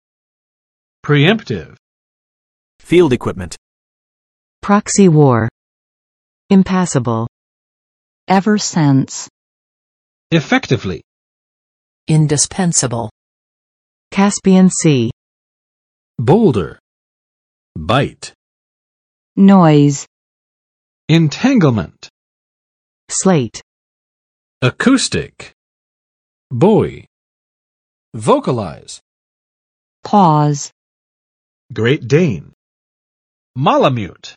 [priˋɛmptɪv] adj. 先发制人的